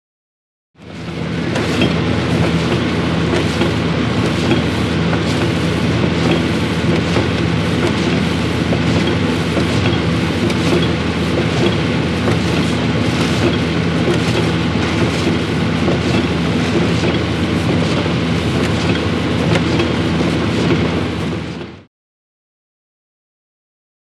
Conveyor Belt
Corn Chute; Corn Passing Up Chute To Silo.